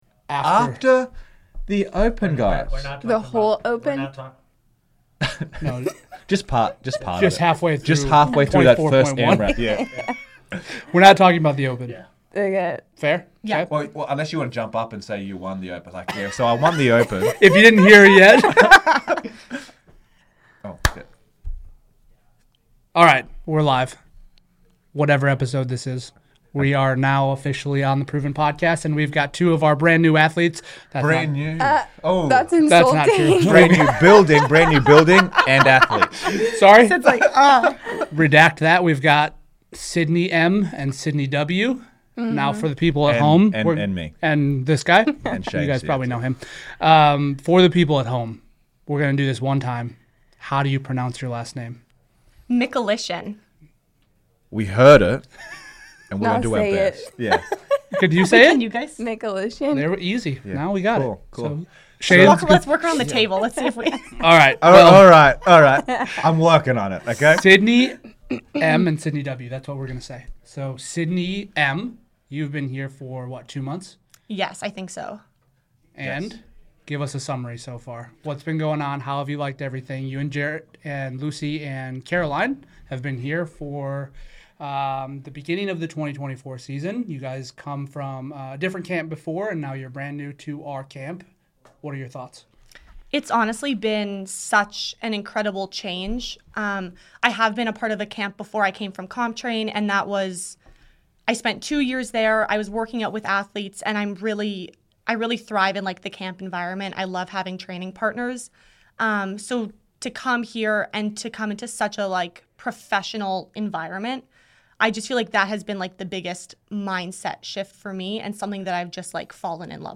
Join us for an episode filled with laughs, stories, and lots of exclusive PRVN content!